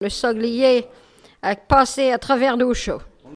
Mémoires et Patrimoines vivants - RaddO est une base de données d'archives iconographiques et sonores.
locutions vernaculaires